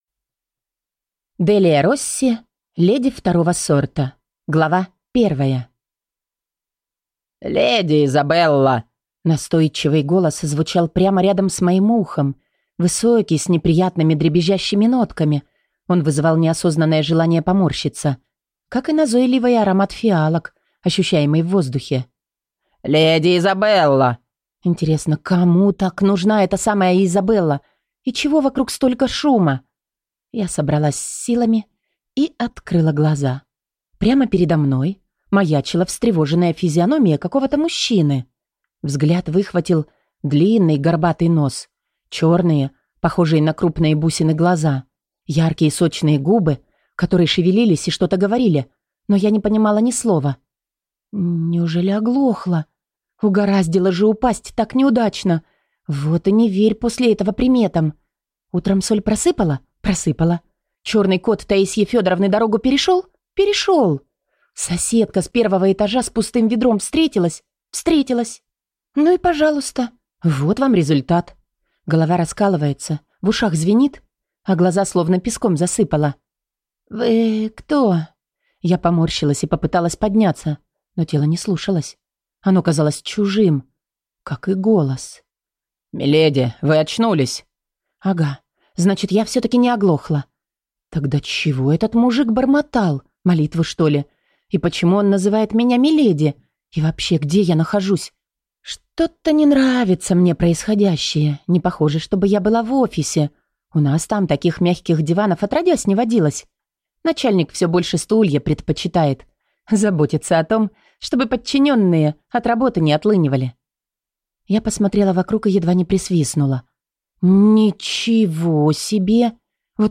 Аудиокнига Леди второго сорта | Библиотека аудиокниг